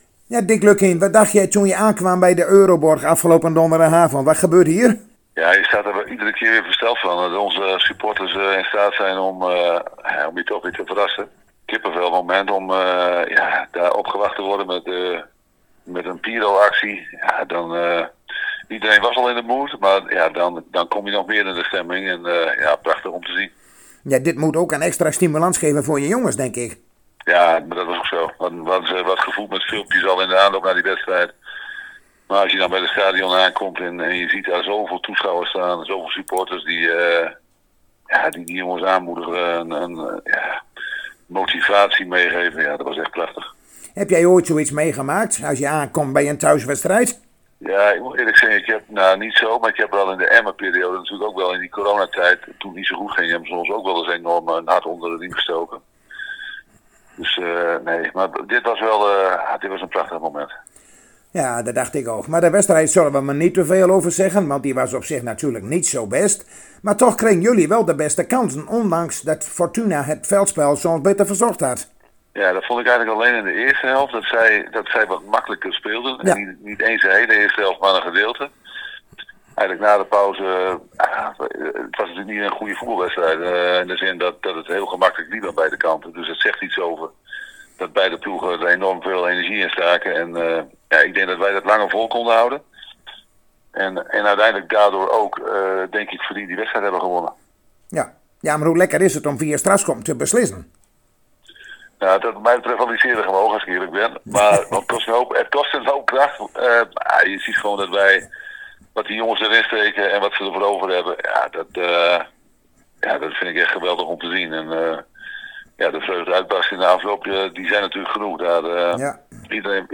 Zojuist spraken wij met Dick Lukkien en natuurlijk keken we even terug op de bekerontmoeting met Fortuna Sittard en we keken vooruit op FC Groningen - TOP Oss.